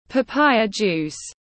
Nước ép đu đủ tiếng anh gọi là papaya juice, phiên âm tiếng anh đọc là /pəˈpaɪ.ə ˌdʒuːs/
Papaya juice /pəˈpaɪ.ə ˌdʒuːs/